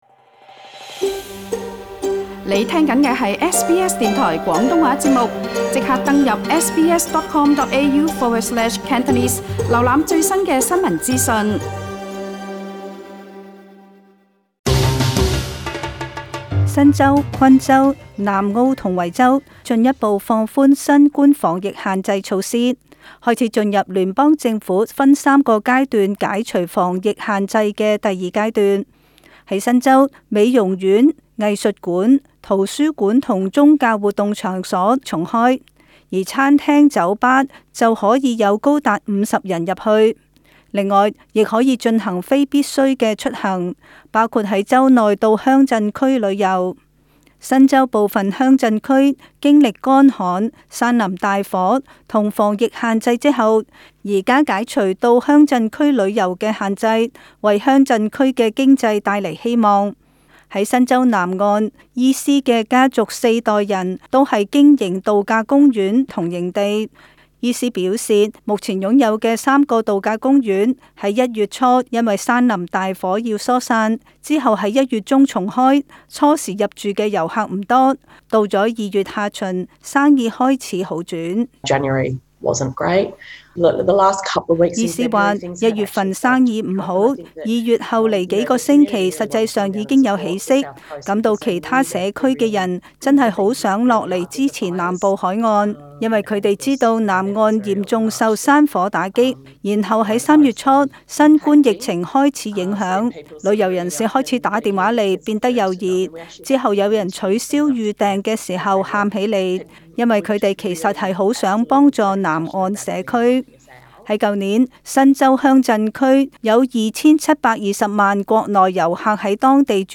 【時事報導】